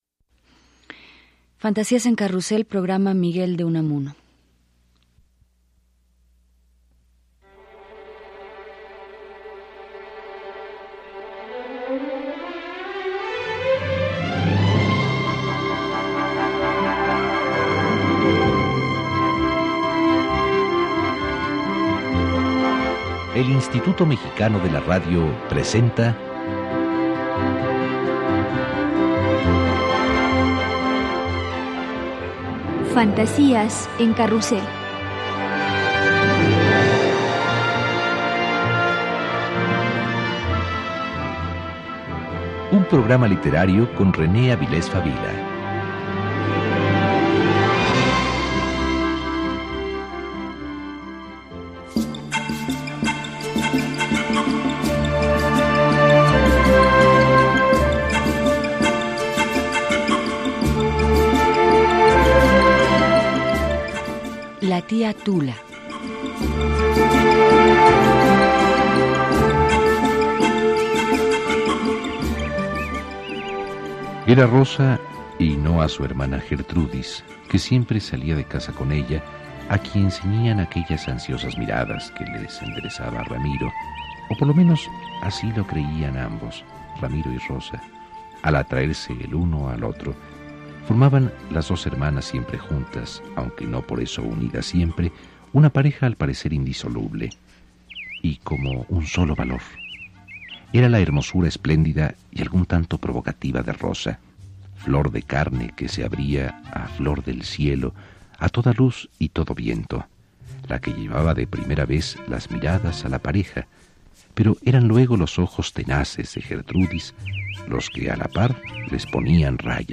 Escucha fragmentos de “La Tía Tula”, y una semblanza de Unamuno, en el programa de René Avilés Fabila, “Fantasías en carrusel”, transmitido en 1995.